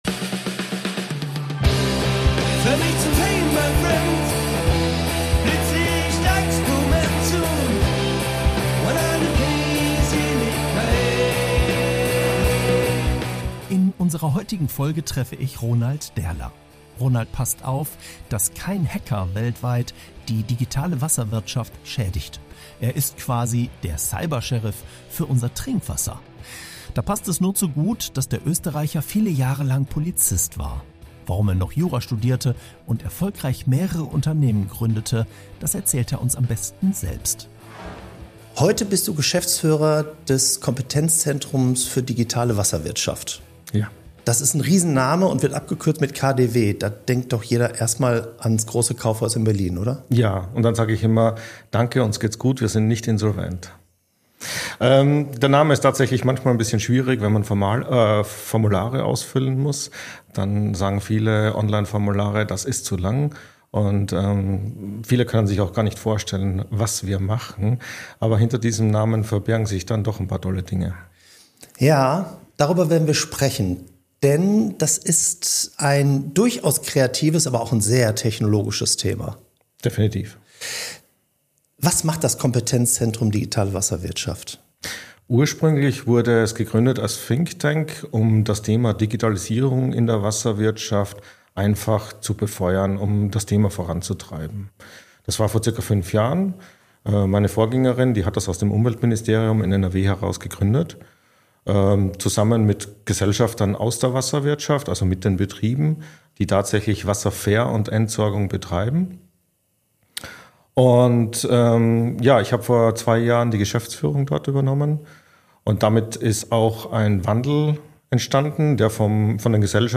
Ein Gespräch über Verantwortung, Klarheit, strategisches Denken – und die Kunst, ruhig zu bleiben, wenn’s ernst wird.